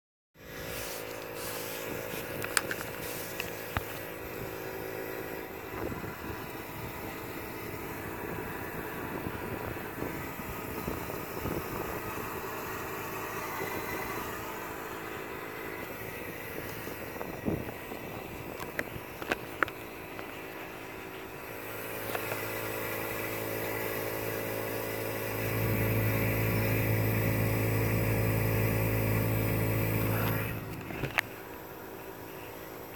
Bruit clim.mp3
Demain si je peux je ferai un autre enregistrement en espérant qu'il y ait moins de vent.
J'avoue que dans l'audio on entend moins le bruit.
Au cas où à partir de 20 secondes et jusqu’à la fin on entend le bruit du compresseur et un autre bruit comme du gaz liquide qui se détend, bruit qui en froid se fait par cycle et à chaud il n'y en a pas du tout.